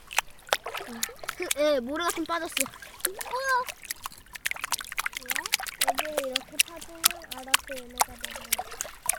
손장구.mp3